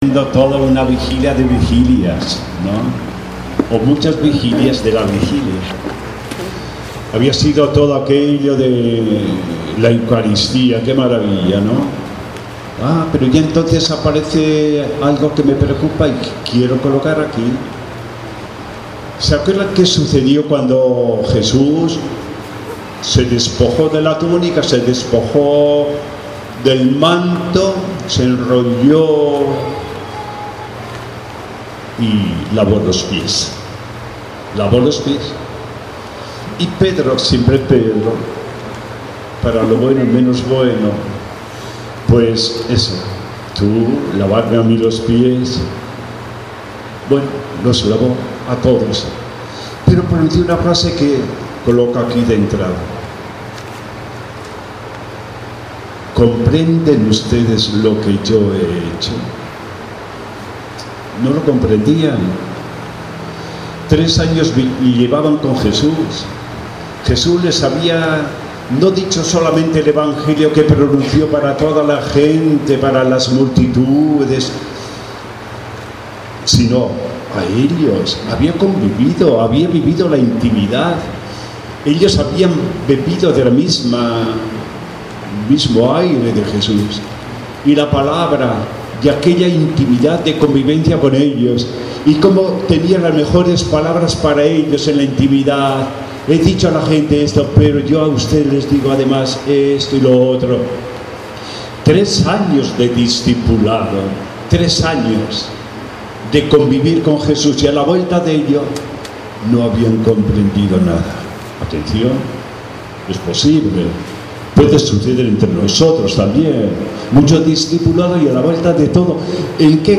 Homilia de Resurreccion